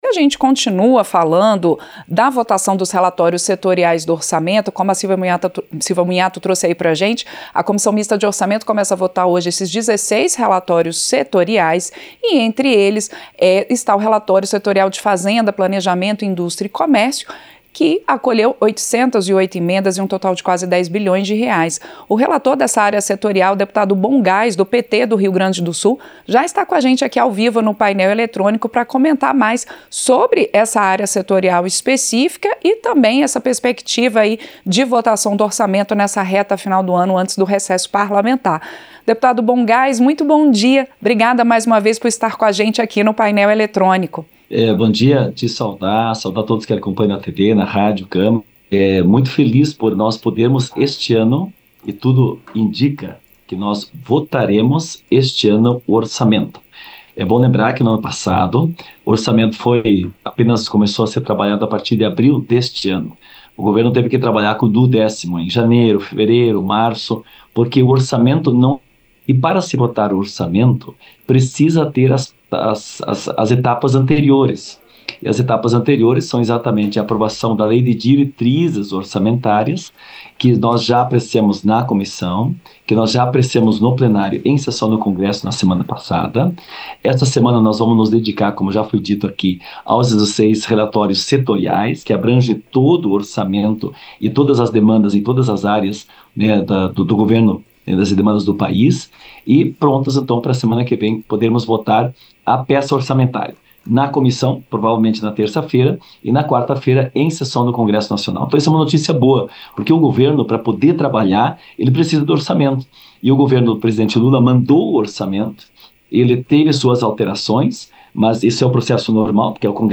Entrevista - Dep. Bohn Gass (PT-RS)